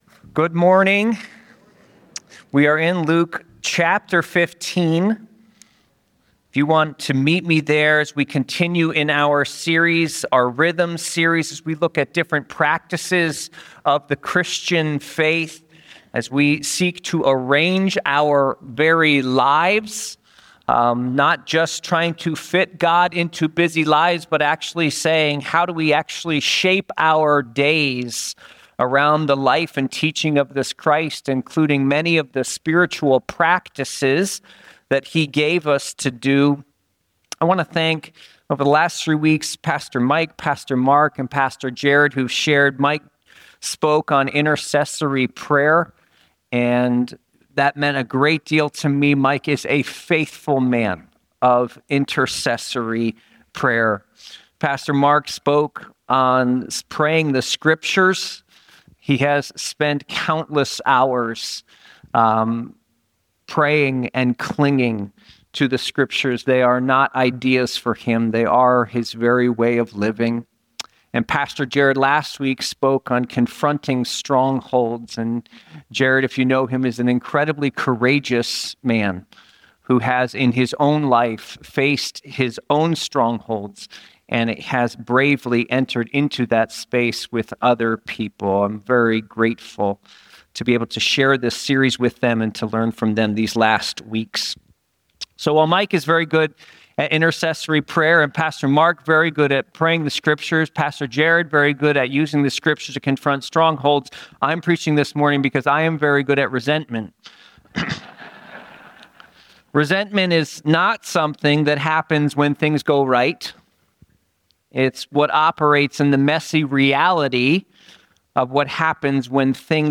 This sermon is centered on Luke 15, discussing the challenges of arranging one's life around Christian practices and the issue of resentment.